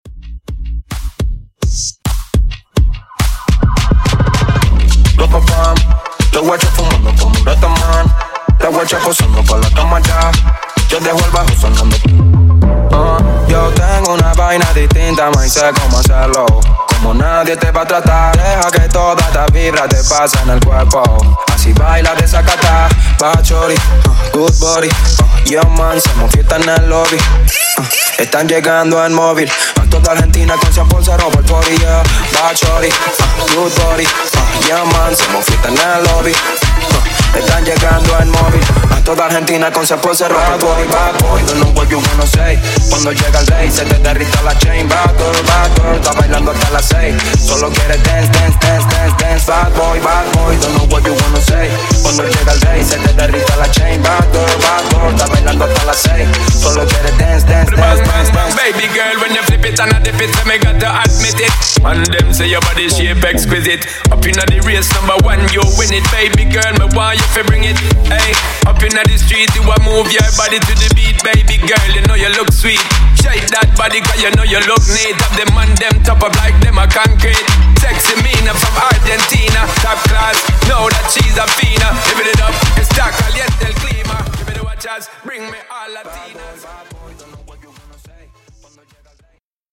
Genre: RE-DRUM
Dirty BPM: 140 Time